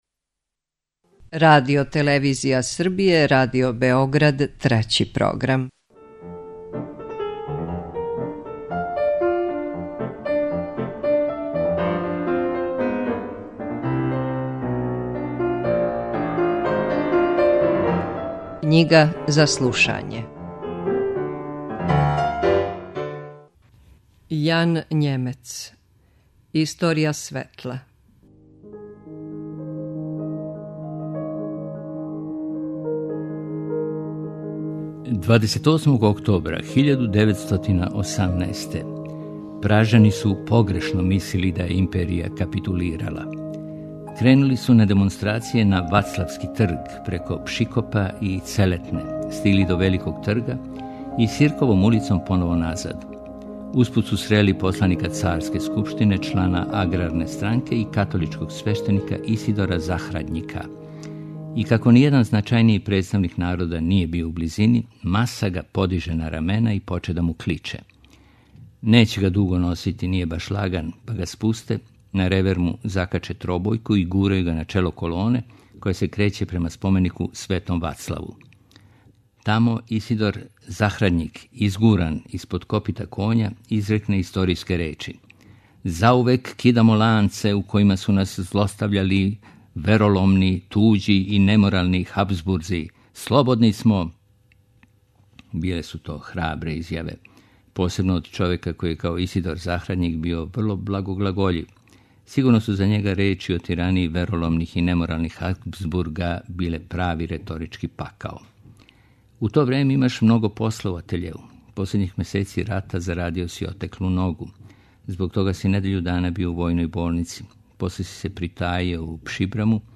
преузми : 9.59 MB Књига за слушање Autor: Трећи програм Циклус „Књига за слушање” на програму је сваког дана, од 23.45 сати.